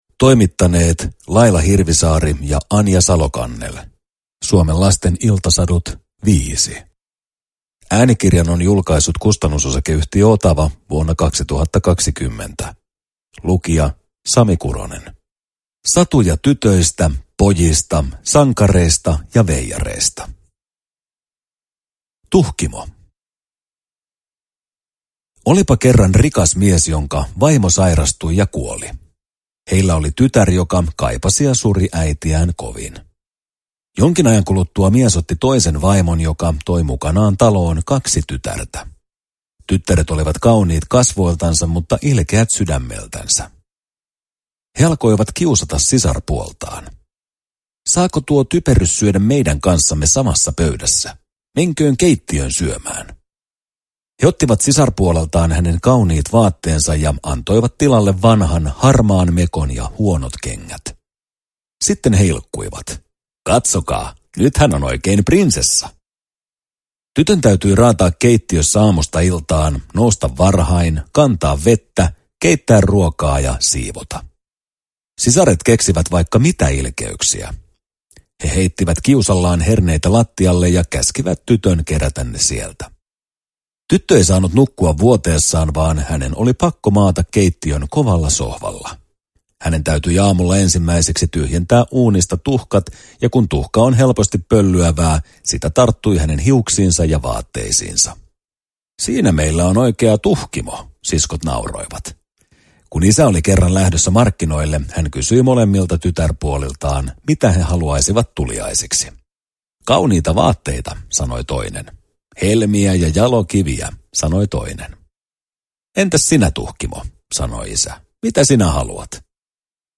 Suomen lasten iltasadut 5 – Ljudbok – Laddas ner